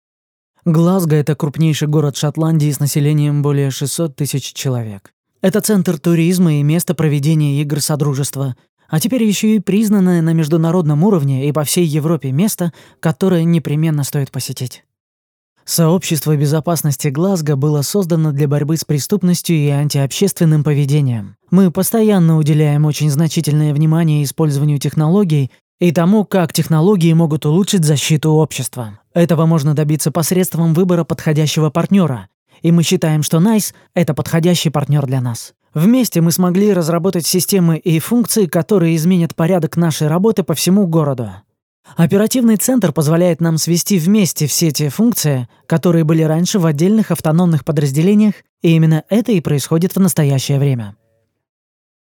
Демо озвучивания